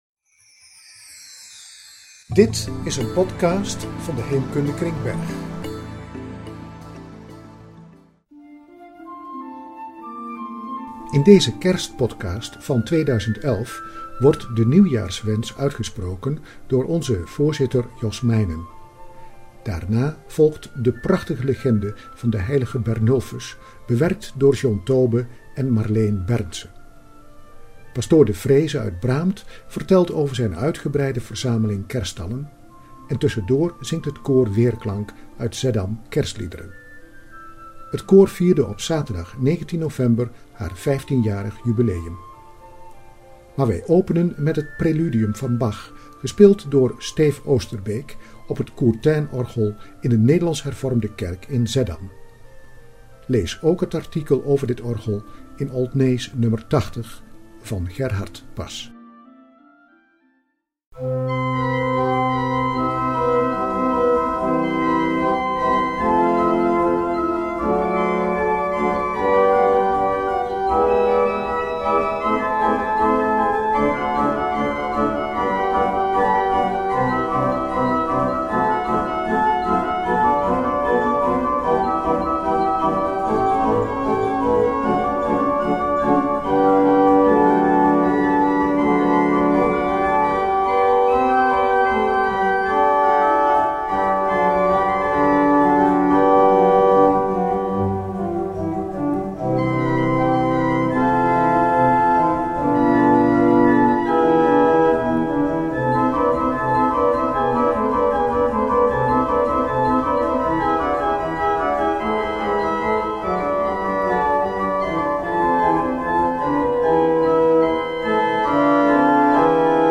Kerstliederen worden gezongen door het dit jaar jubilerende koor Weerklank. En we horen het Courtainorgel in Zeddam dat in de belangstelling staat.